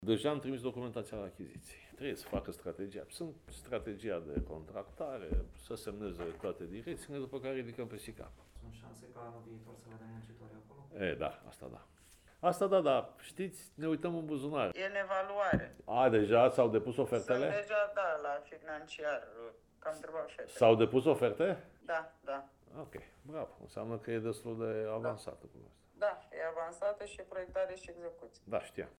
În cadrul unui interviu pe care l-a acordat postului Radio Constanța